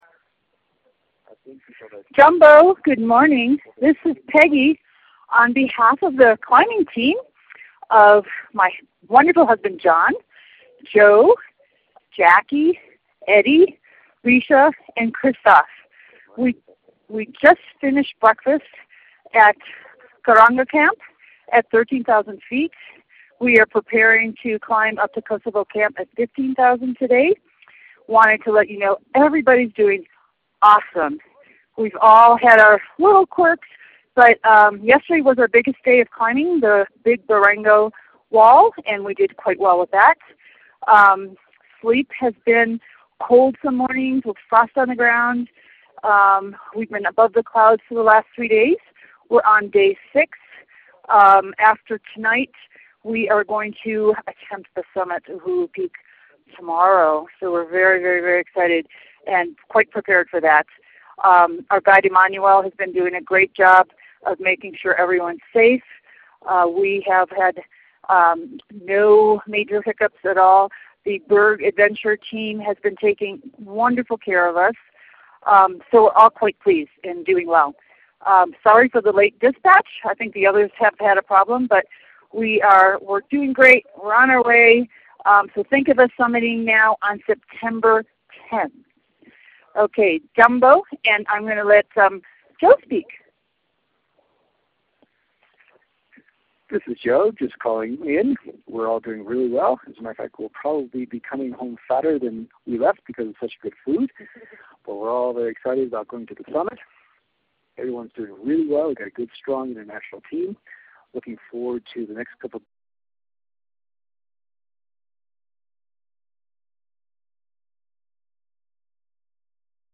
September 9, 2012 – Jambo from Karanga Camp!